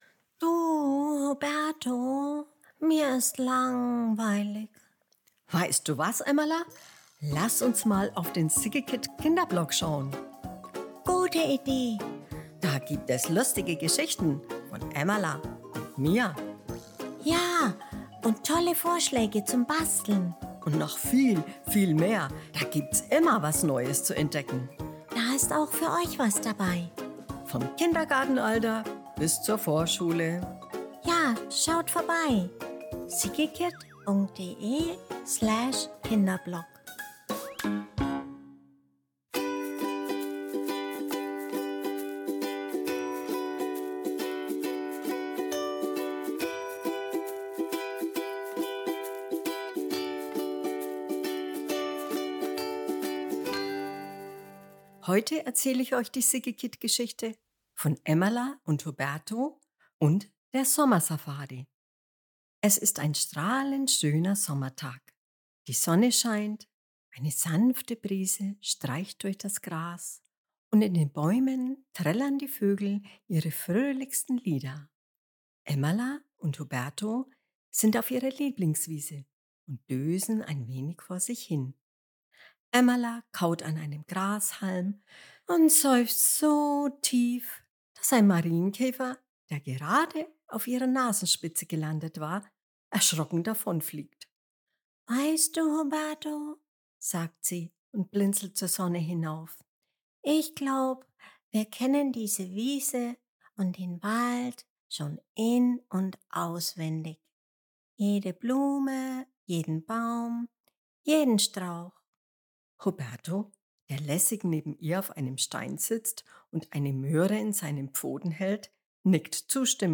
Emmala, Huberto und die Sommersafari July 17, 2025 Kinderblog Sommer, Vorlesegeschichten, Emmala & Huberto Ein Sommertag voller Abenteuer: Emmala und Huberto glauben, Wald und Wiese längst in- und auswendig zu kennen. Doch Gildehard lädt sie zu einer Sommersafari ein – und plötzlich sehen sie alles mit neuen Augen.